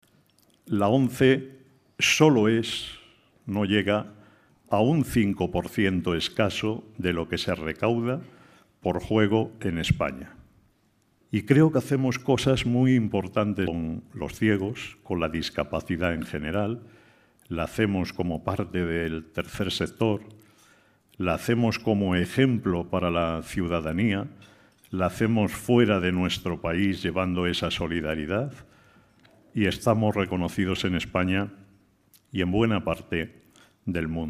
En una conferencia organizada por Fórum Europa pasa revista a la actualidad social y reclama "respeto y reconocimiento" para las organizaciones de la sociedad civil